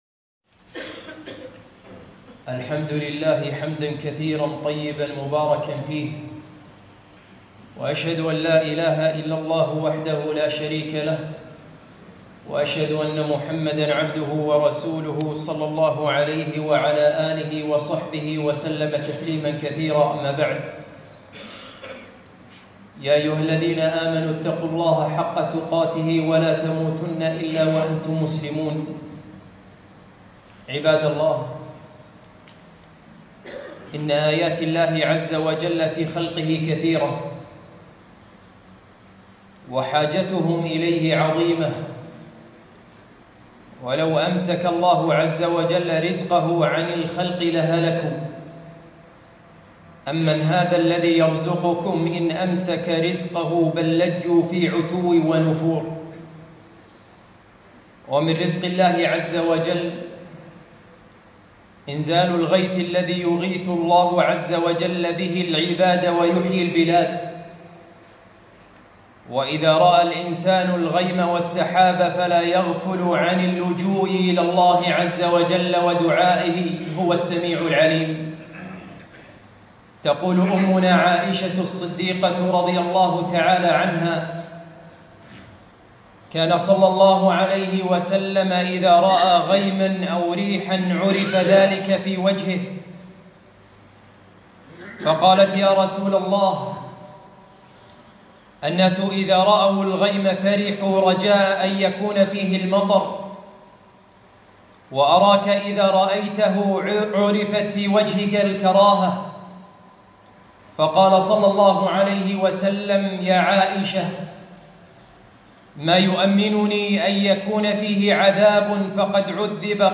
خطبة - المطر والغنيمة الباردة